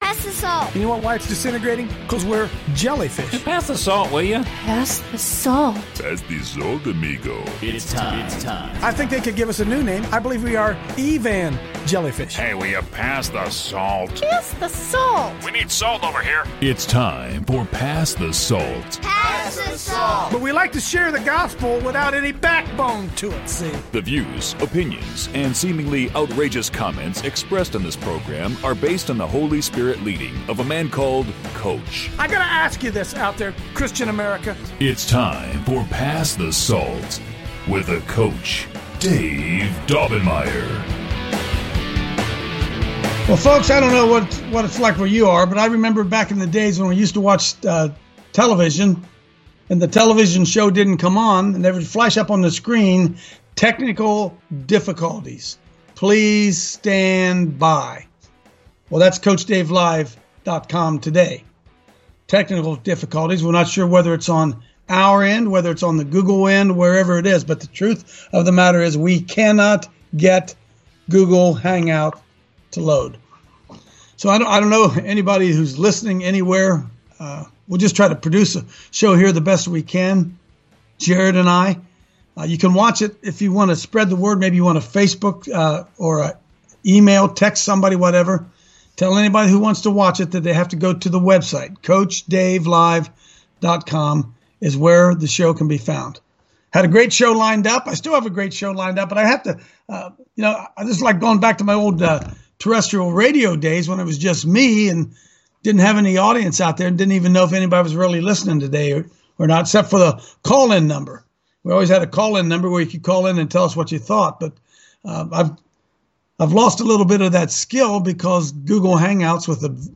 a Bible Study on Vision today